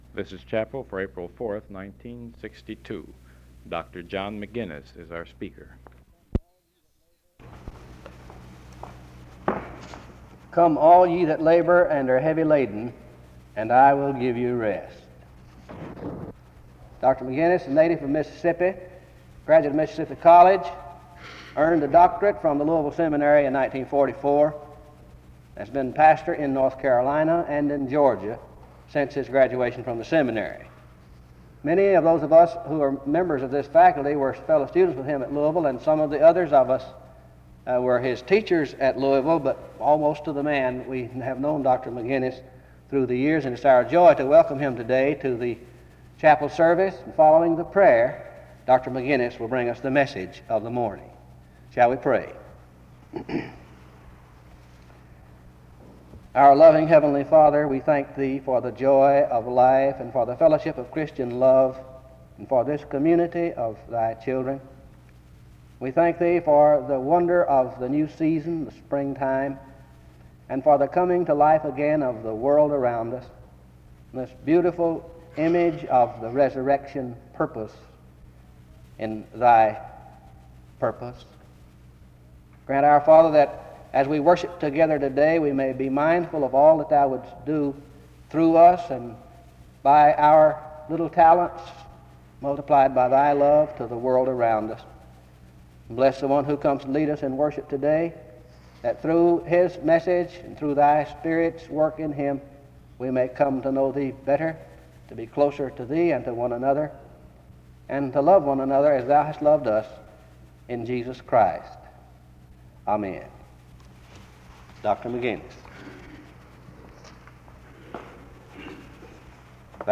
To rob Jesus of His uniqueness forfeits the entire gospel message (20:32-22:51). He ends in prayer (22:52-23:32), and the service closes in choral singing (23:33-23:48).